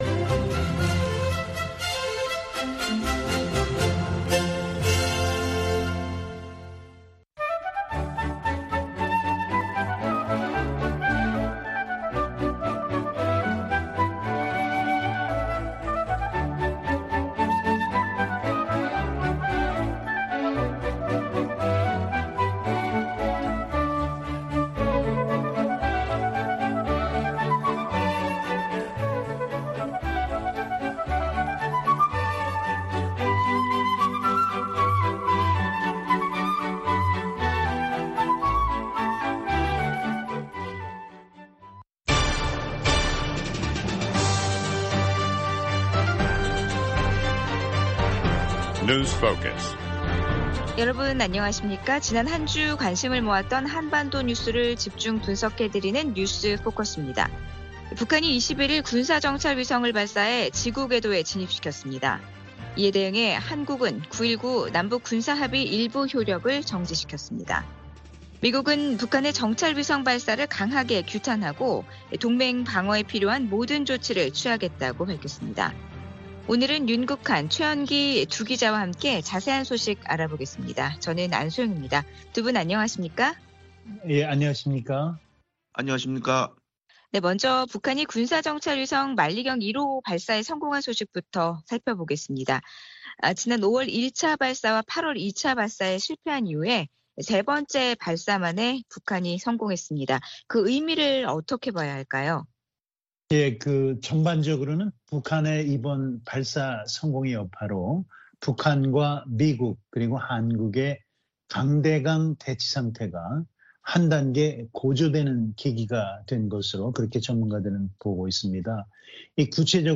VOA 한국어 방송의 월요일 오전 프로그램 2부입니다. 한반도 시간 오전 5:00 부터 6:00 까지 방송됩니다.